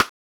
08 rim hit_2.wav